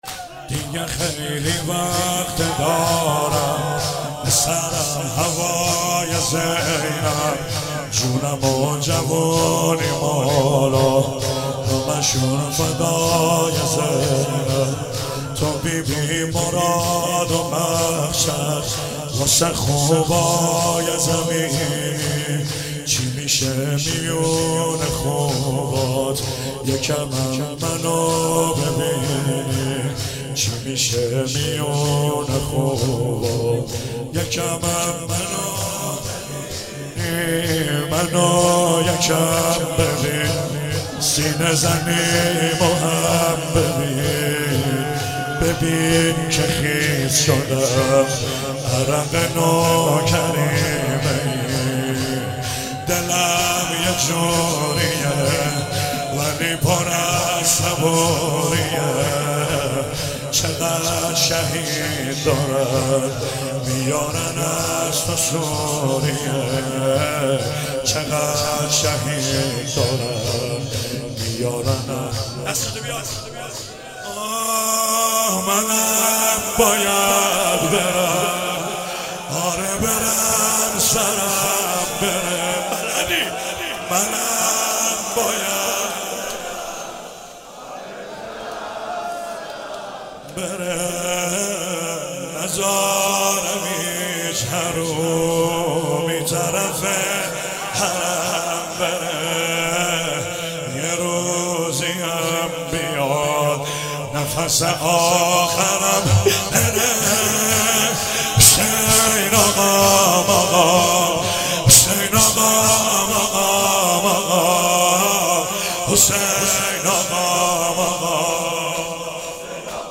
واحد، زمینه